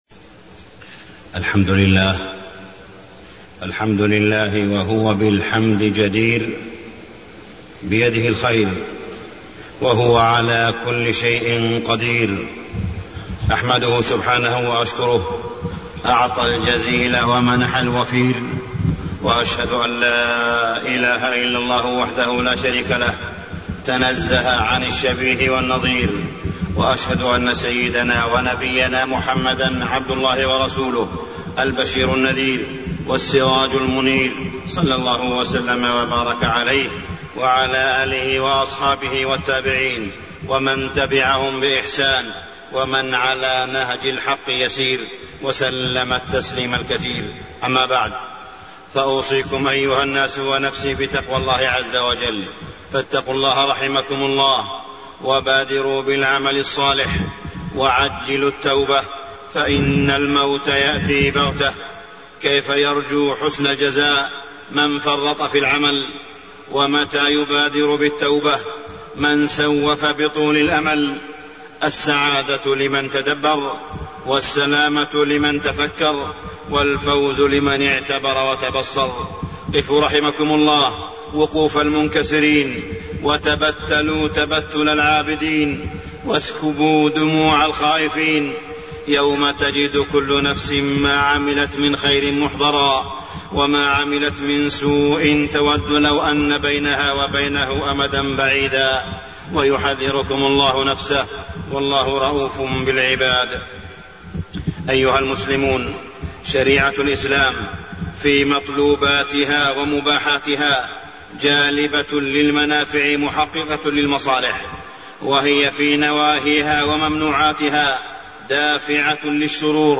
تاريخ النشر ٢٧ ربيع الأول ١٤٢٩ هـ المكان: المسجد الحرام الشيخ: معالي الشيخ أ.د. صالح بن عبدالله بن حميد معالي الشيخ أ.د. صالح بن عبدالله بن حميد التدخين